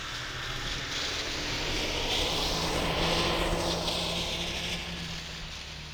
Hybrid Subjective Noise Event Audio File (WAV)
Hybrid Snowmobile Description Form (PDF)
mcgill_hybrid_2007.wav